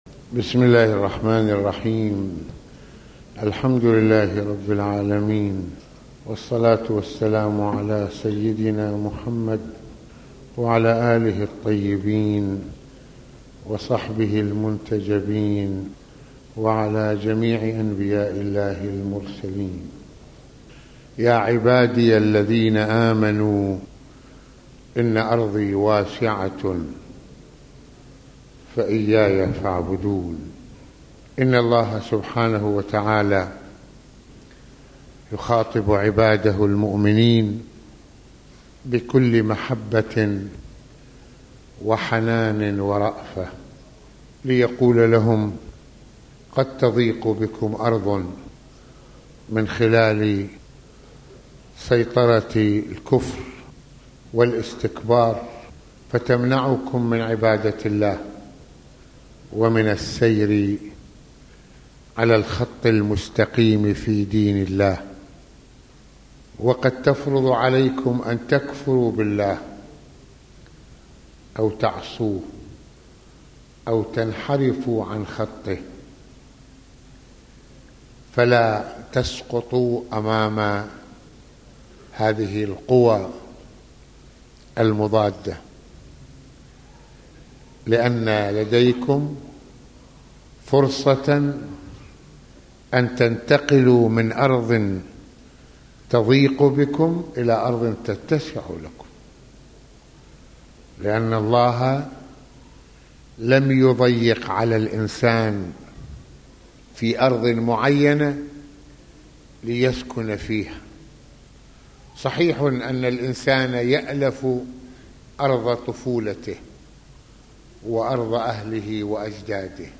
- المناسبة : موعظة ليلة الجمعة المكان : مسجد الإمامين الحسنين (ع) المدة : 26د | 21ث المواضيع : الاسلام وعلاقة الانسان بالارض - الهجرة في سبيل عبادة الله ورفض الظالمين - أجر الصابرين - الذين آمنو يوم القيامة.